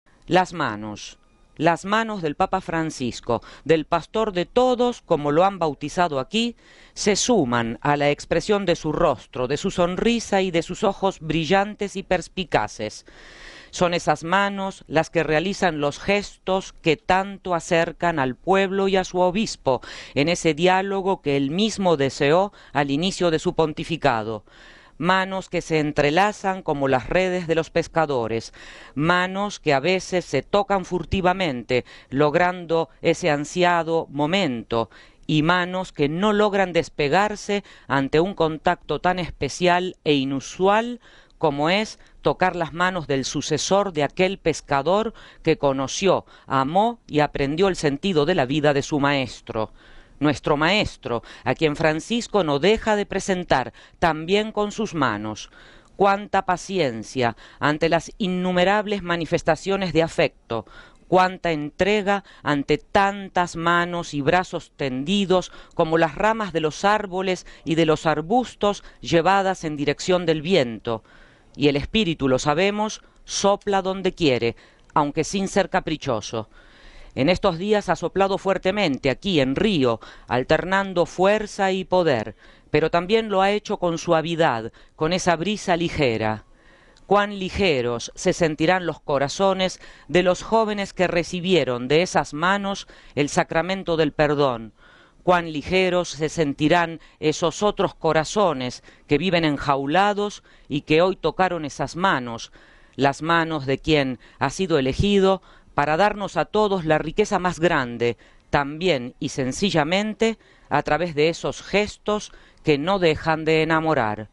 (RV).- (Con Audio) Besos, caricias, abrazos, saludos…las manos del Papa en este primer viaje internacional hablan por él, son su voz expresiva y plástica, cuando se mueve entre la multitud de Rio, son el amor emblemático con el que llega a todo el mundo, desde los niños a los ancianos.